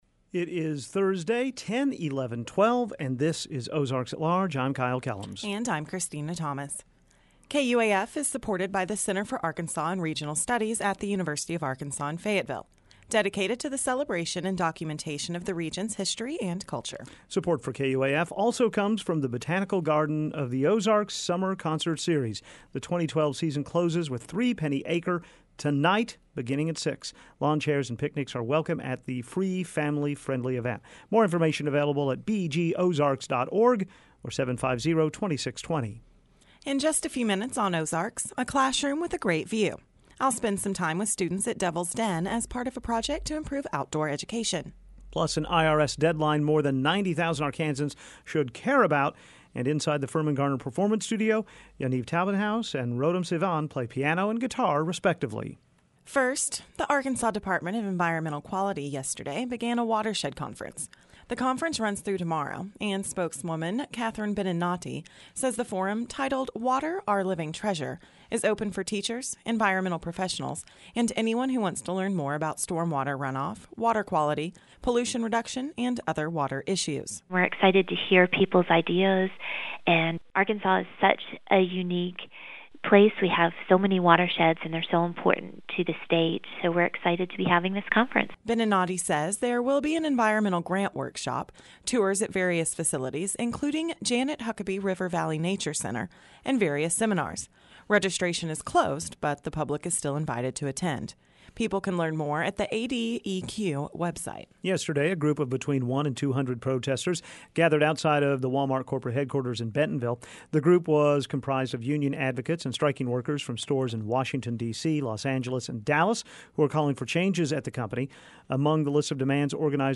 Audio: oalweb101112.mp3 On this edition of Ozarks, a classroom with a great view. We spend some time with students at Devil’s Den as part of a project to improve outdoor education.